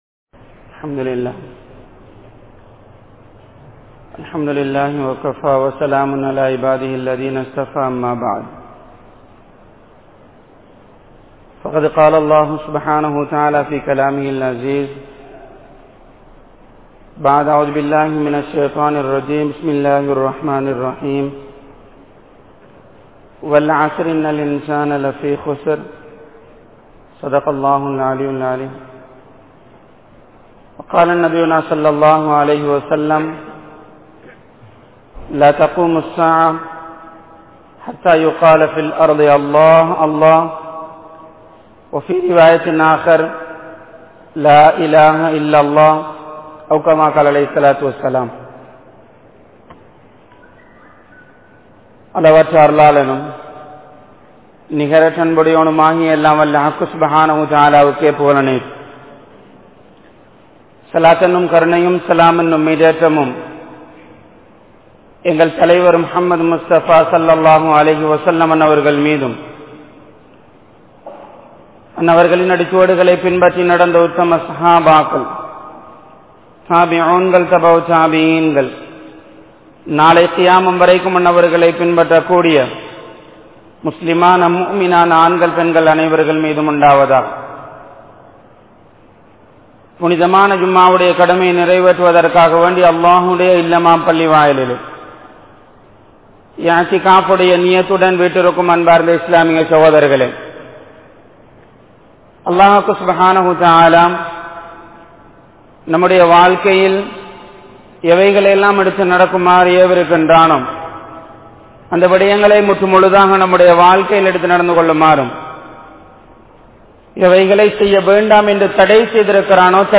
Oru Muslimin Perumathi (ஒரு முஸ்லிமின் பெறுமதி) | Audio Bayans | All Ceylon Muslim Youth Community | Addalaichenai